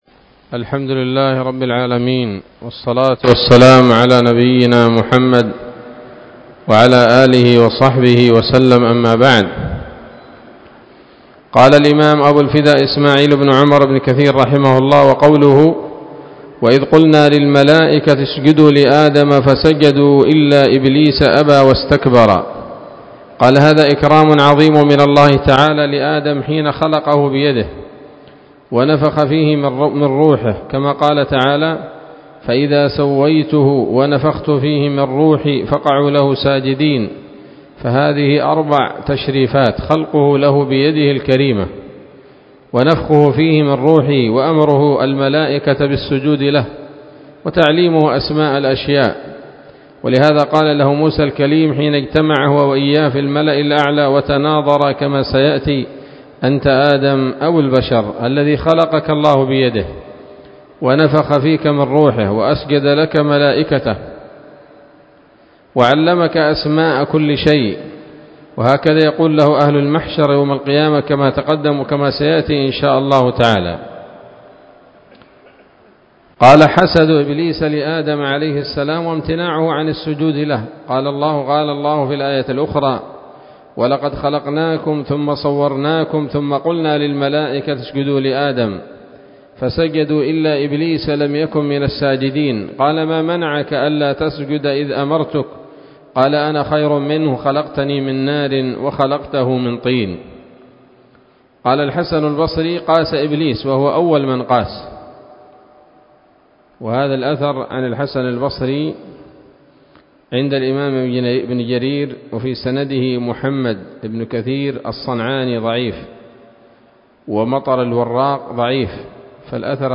الدرس الثالث من قصص الأنبياء لابن كثير رحمه الله تعالى